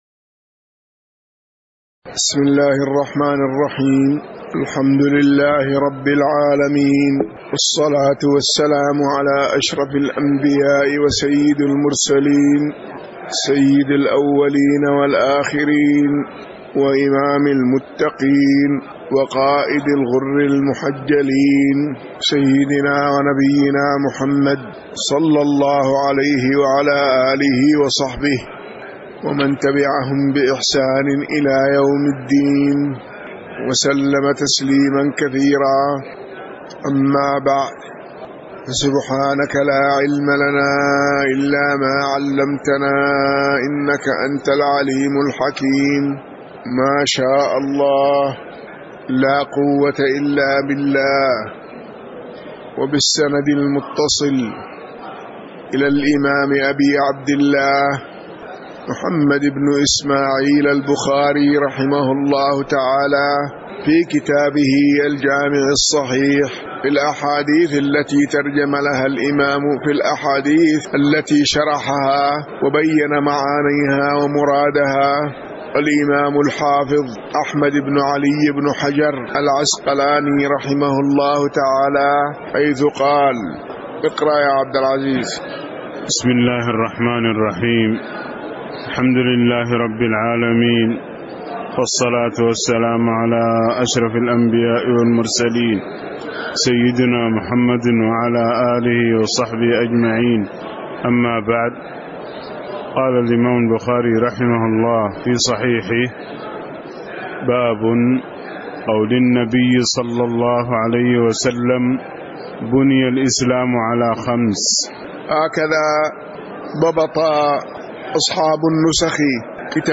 تاريخ النشر ٢١ محرم ١٤٣٩ هـ المكان: المسجد النبوي الشيخ